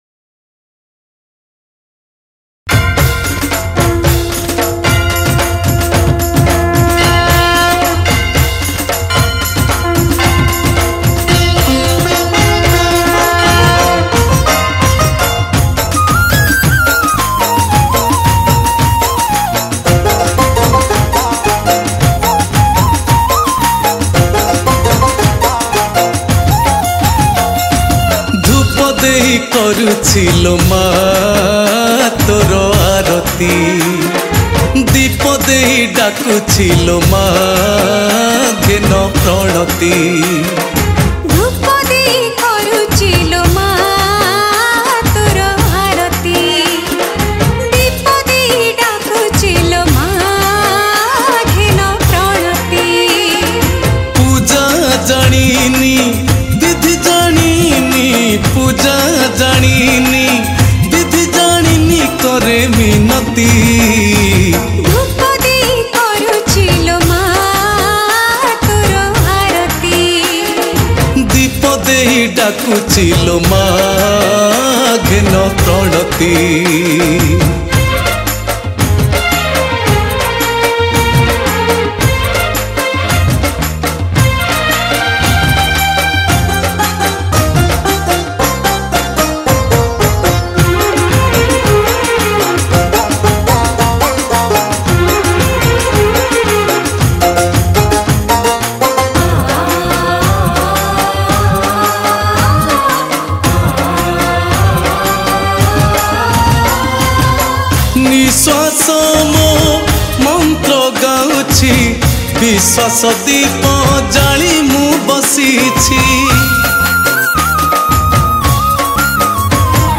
Category: Durga Puja Special Odia Songs